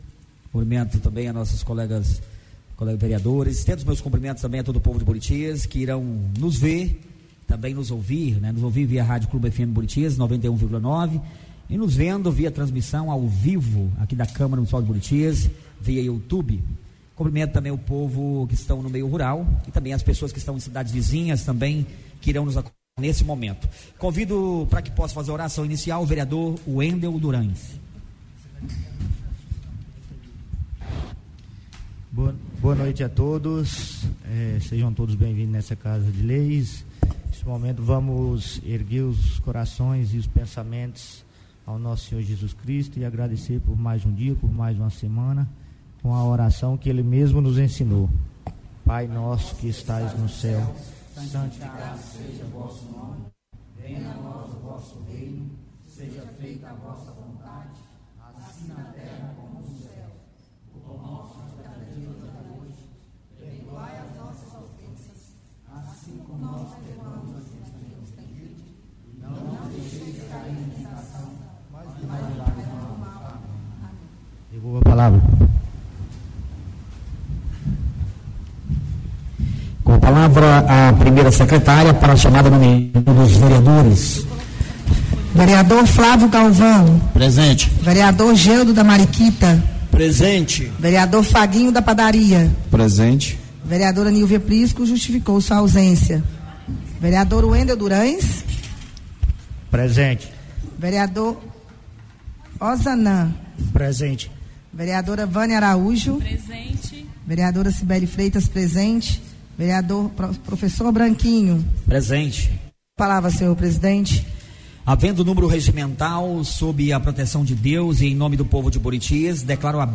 38ª Reunião Ordinária - 27-11-23